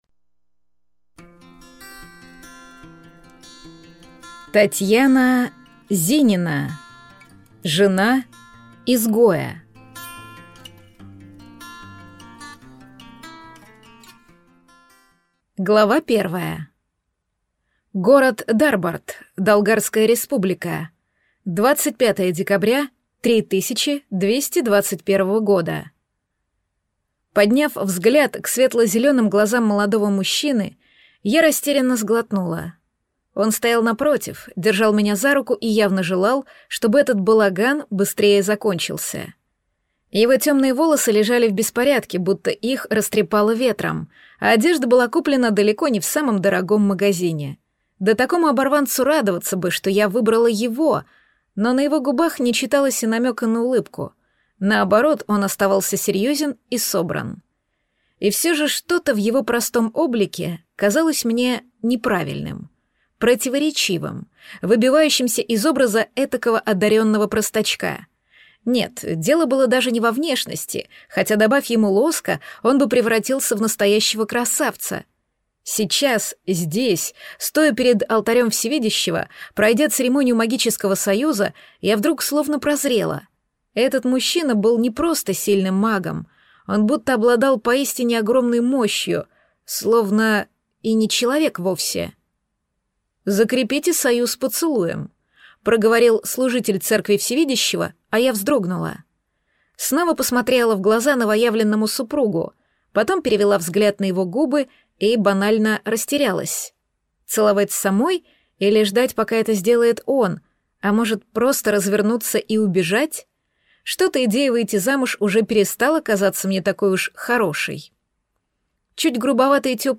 Аудиокнига Жена изгоя | Библиотека аудиокниг